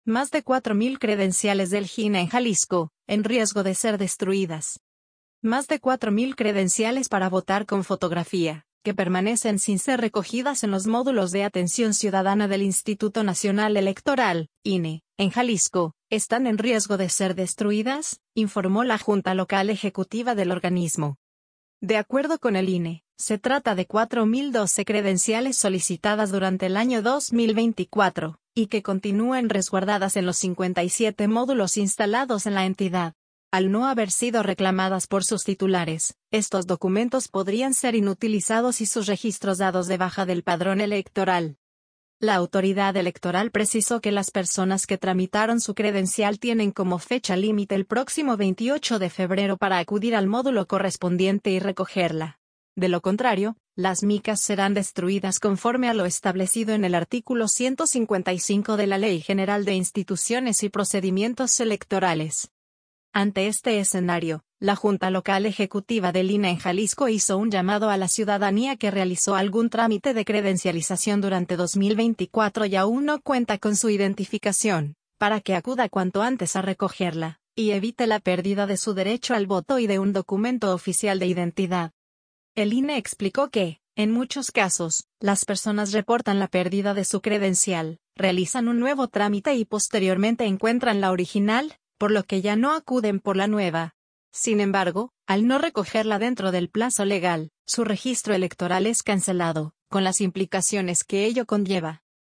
Transcripción de texto a voz